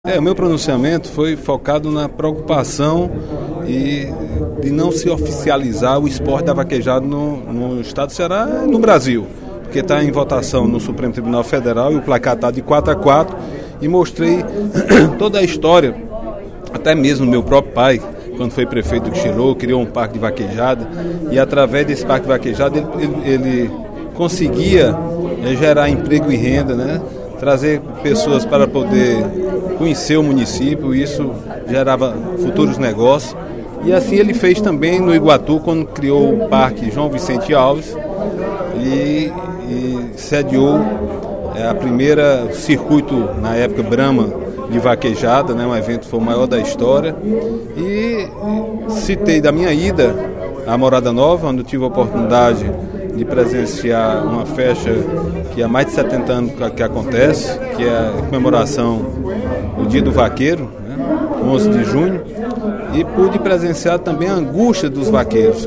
O deputado Agenor Neto (PMDB) manifestou, durante o primeiro expediente da sessão plenária desta quarta-feira (15/06), preocupação com a possibilidade de a vaquejada não ser oficializada como esporte no Brasil, sobretudo no Ceará.